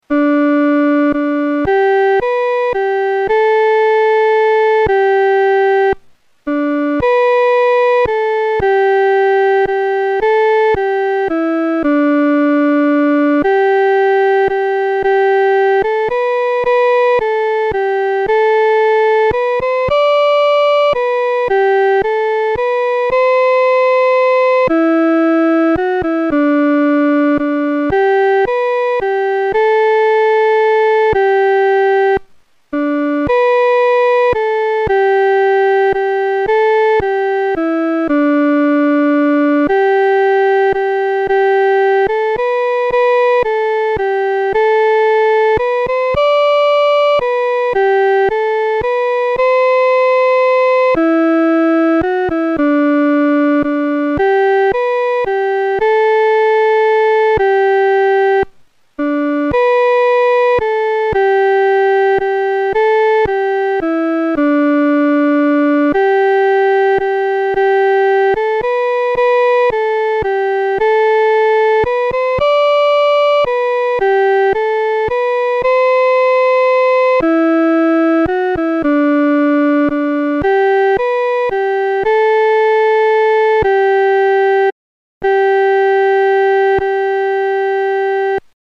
伴奏
女高
指挥在带领诗班时，表情和速度应采用温柔而缓慢地。